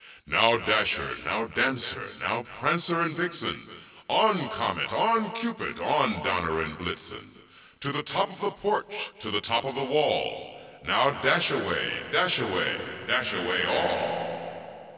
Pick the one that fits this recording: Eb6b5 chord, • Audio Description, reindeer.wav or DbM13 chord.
reindeer.wav